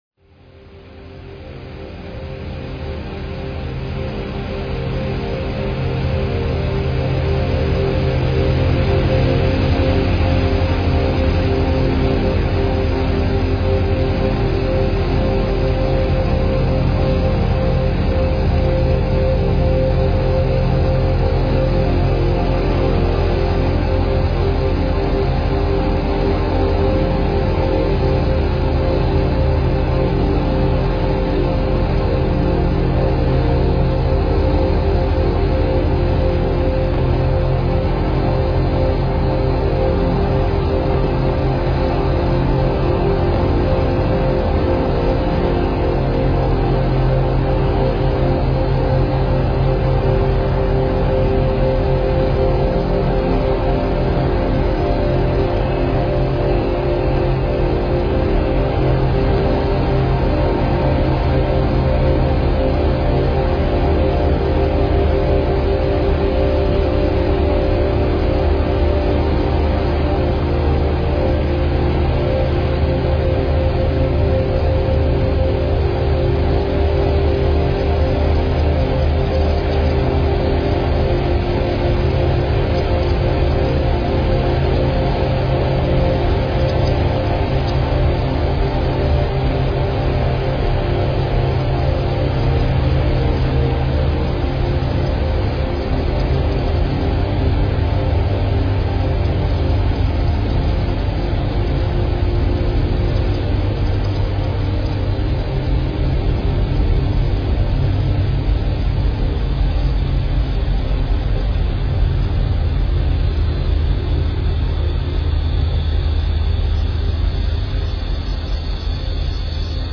К сожалению первый трек слегка обрезан.